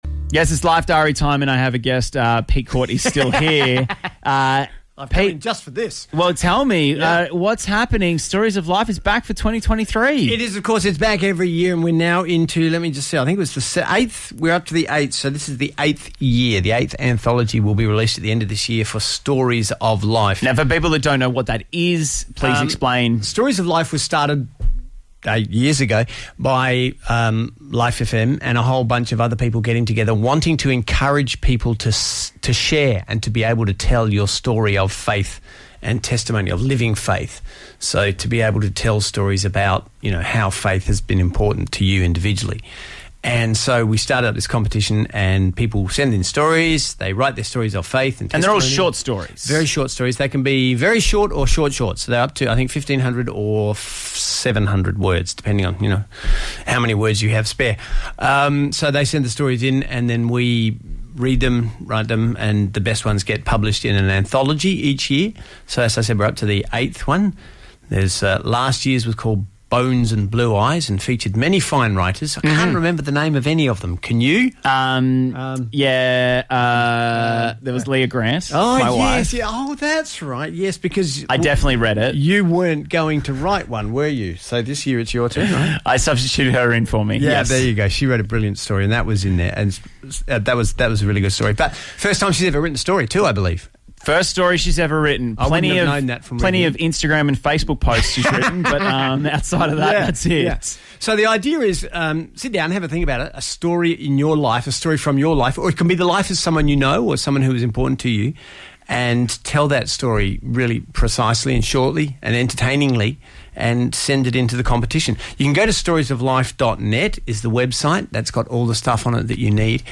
chatting about Stories of Life 2023